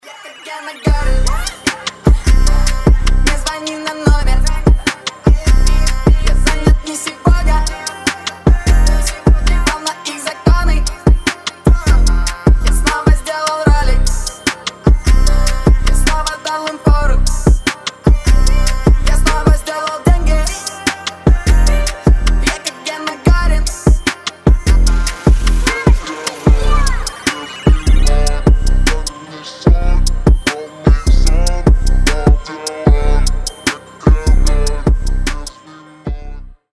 • Качество: 192, Stereo
русский рэп
басы